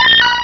Cri de Miaouss dans Pokémon Rubis et Saphir.